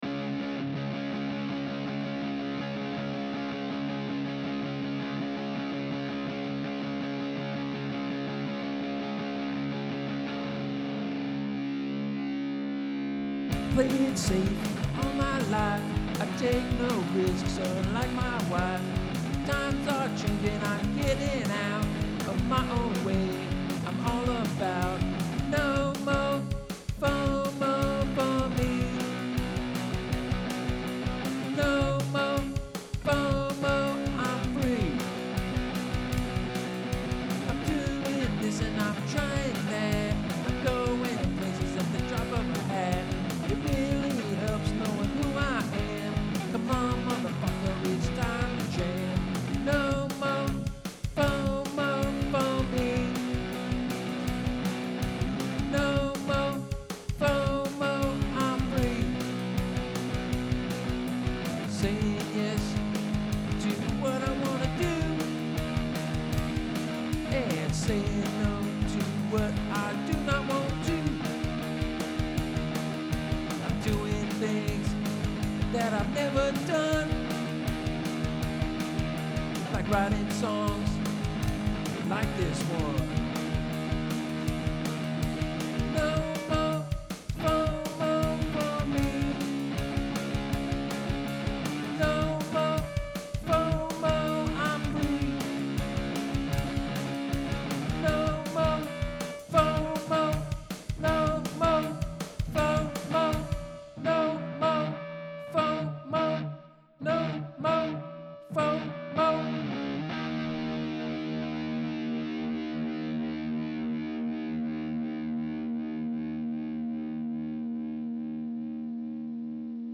Write a song using repetitive, angular melodic structures that rise and fall in pitch.